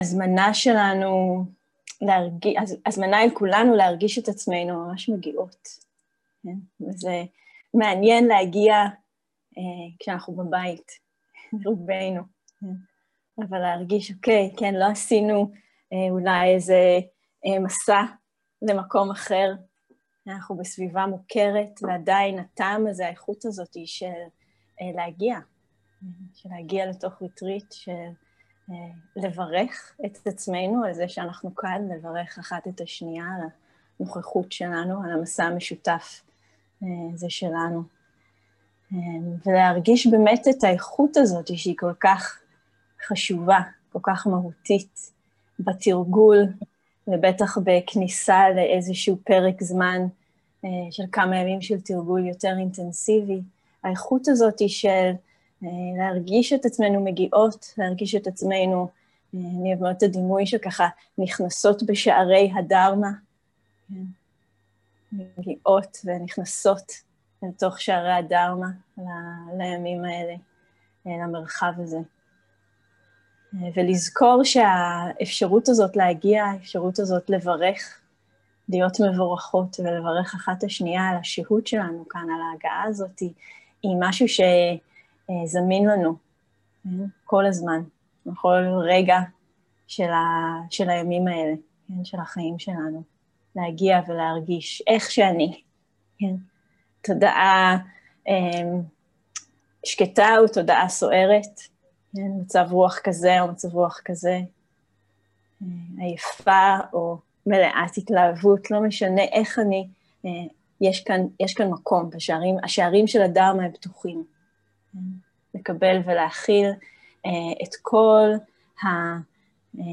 סוג ההקלטה: שיחת פתיחה
איכות ההקלטה: איכות גבוהה